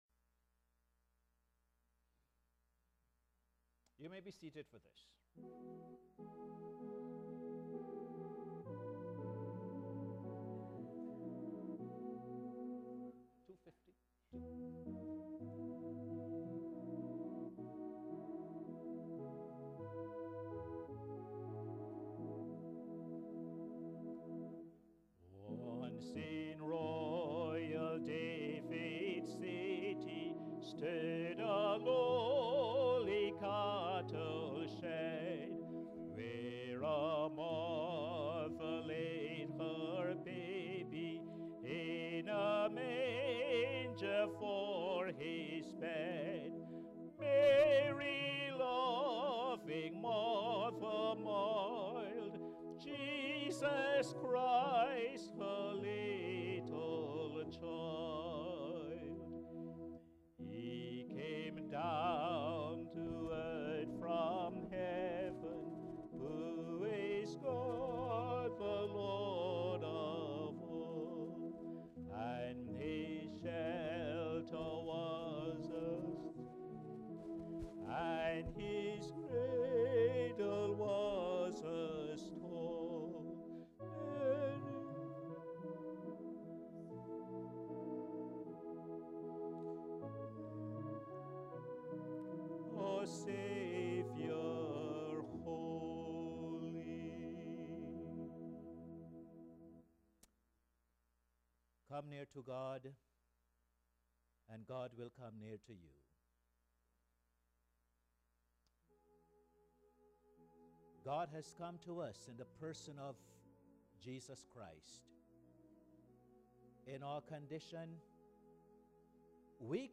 Posted in Sermons on 29. Dec, 2011